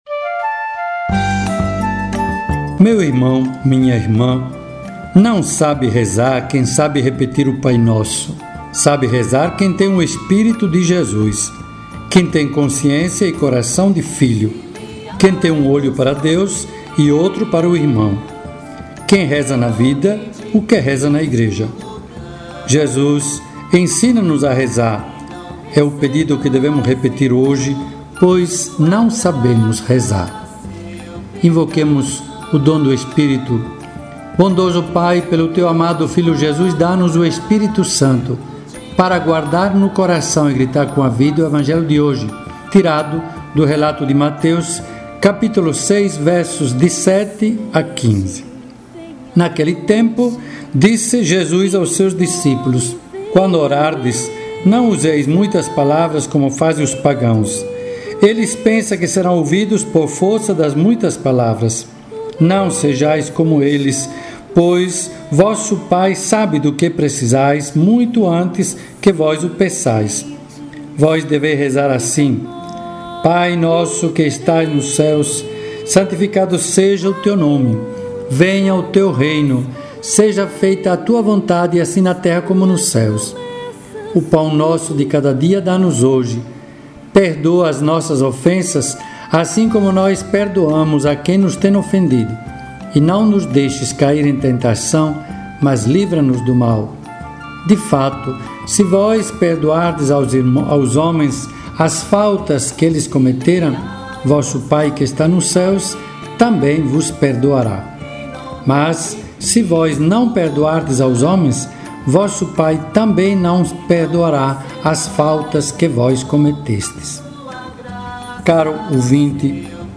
Quaresma 5º dia: reflexão do dia com Dom Egídio Bisol